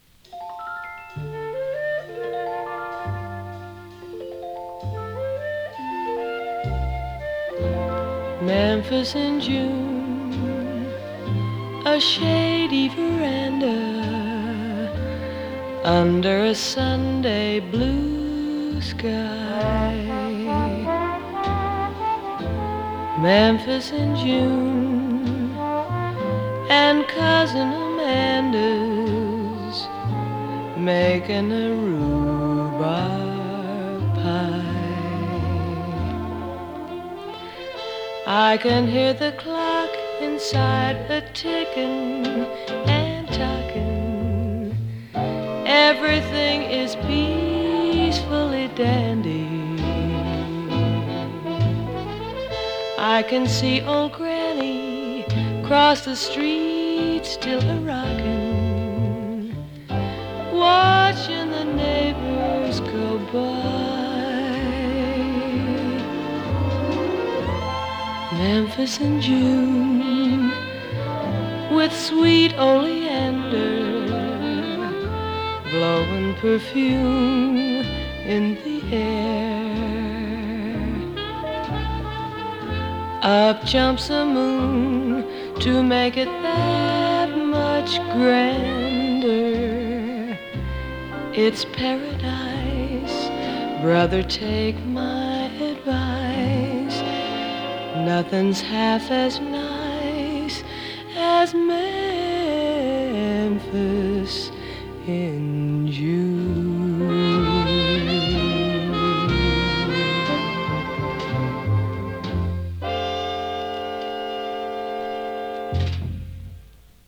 カリフォルニアはサンタローザ出身の白人シンガー。
所々軽いパチ・ノイズ。